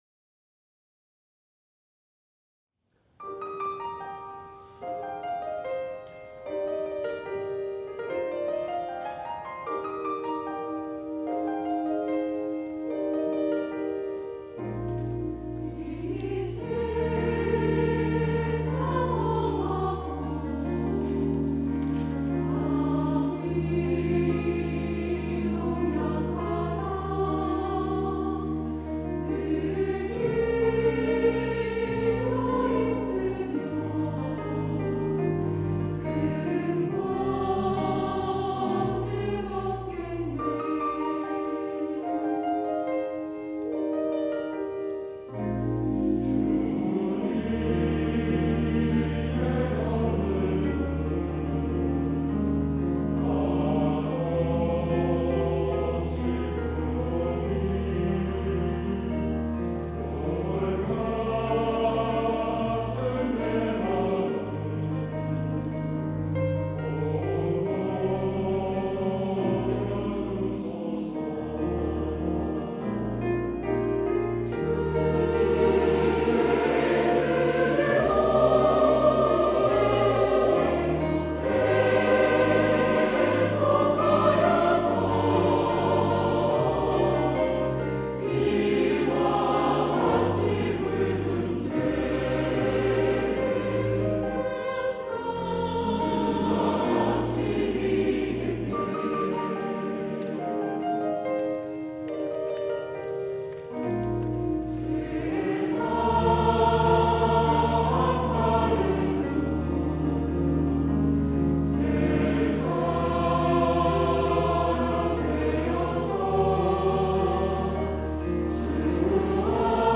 시온(주일1부) - 이 세상 험하고
찬양대